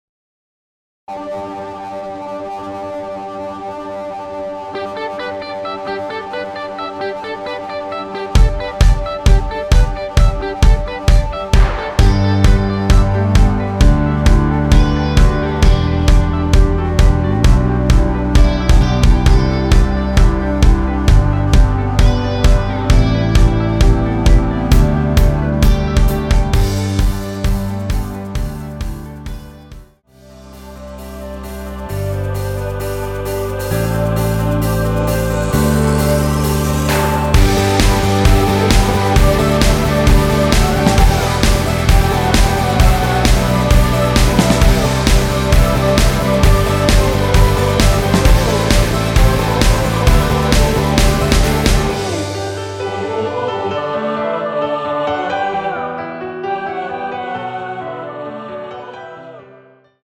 원키에서(-2)내린 멜로디 포함된 MR입니다.(미리듣기 확인)
앞부분30초, 뒷부분30초씩 편집해서 올려 드리고 있습니다.